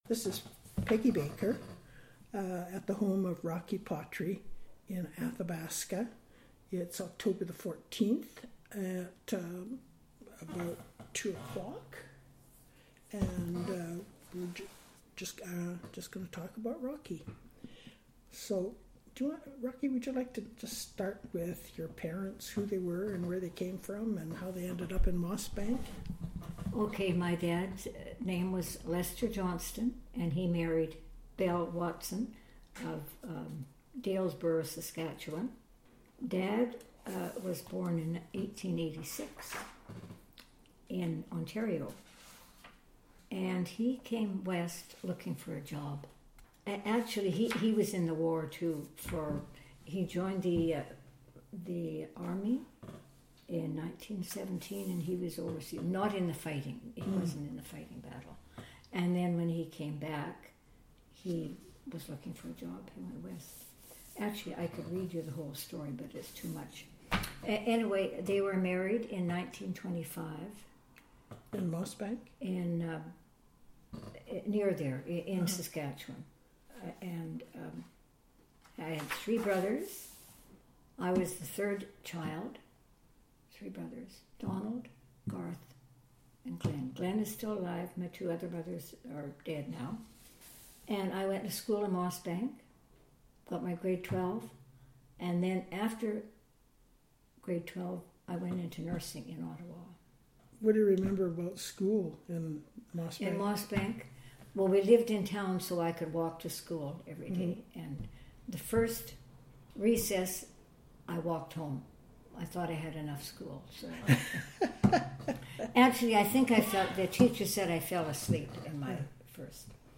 Audio interview,